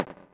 tick.ogg